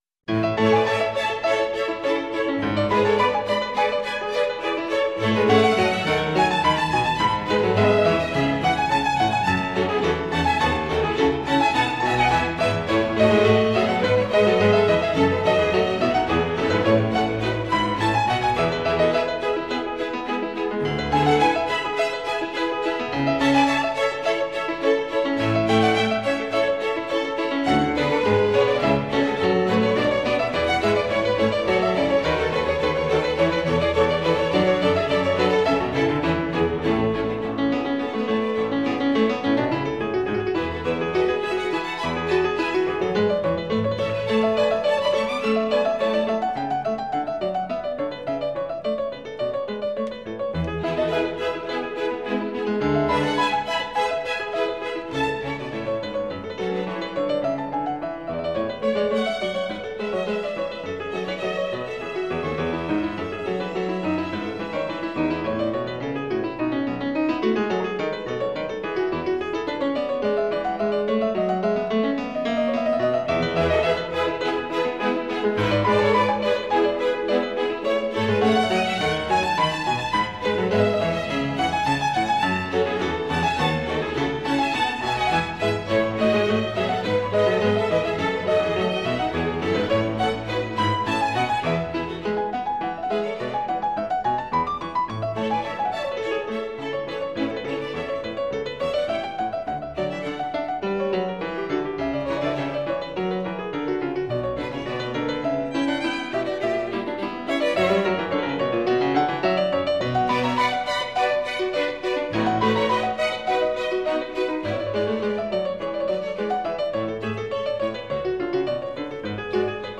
03_Parable_Sower_JPEG_1024The harpsichord concertos, BWV 1052–1065, are concertos for harpsichord, strings and continuo by Johann Sebastian Bach.